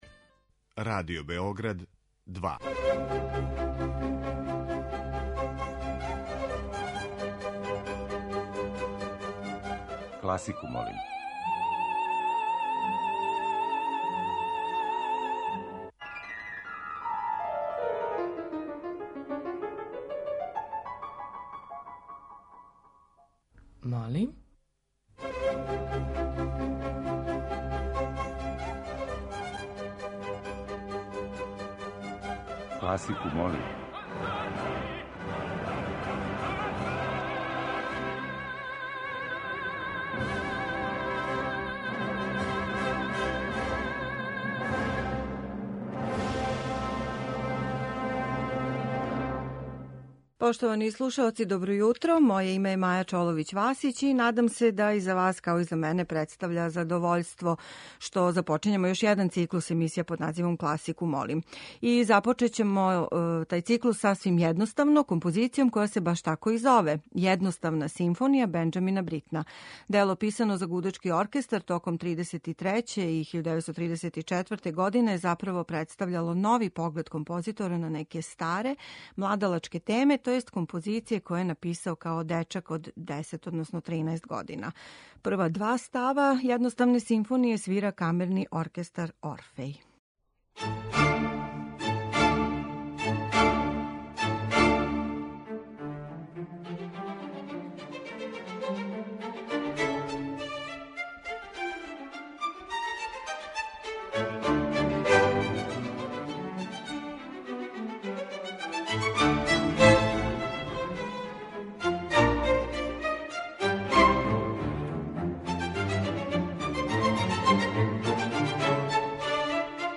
Kласика у необичним аранжманима
Нови циклус емисија Класику молим, као и обично доноси избор стилски и жанровски разноврсних музичких предлога који ове недеље употпуњује неколико обрада за инструменте или ансамбле нетипичне за класичну музику.